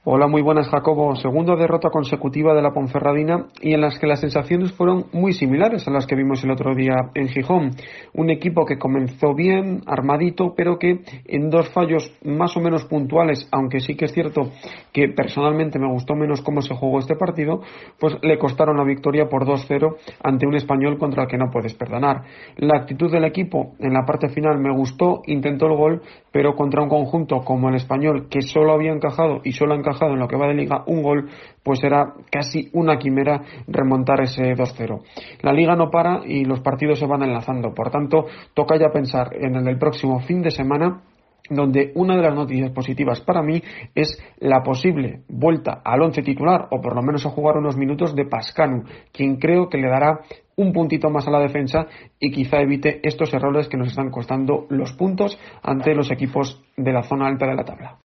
DCB-TIEMPO DE OPINIÓN